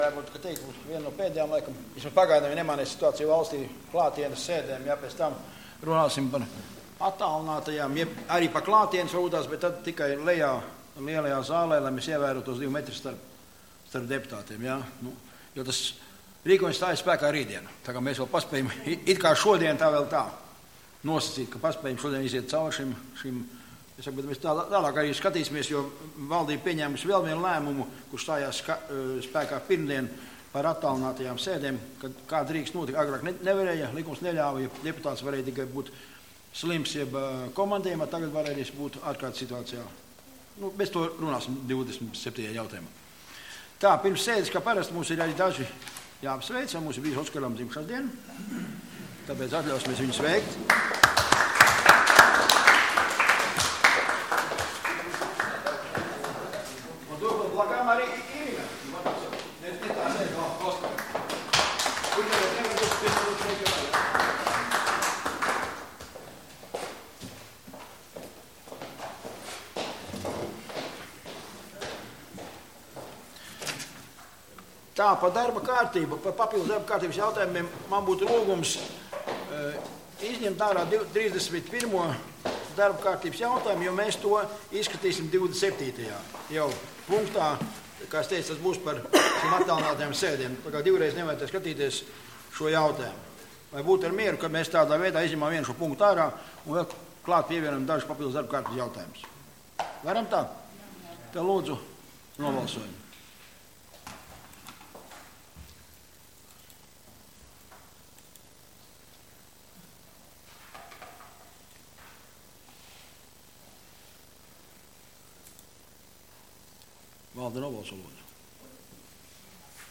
Domes sēde Nr. 19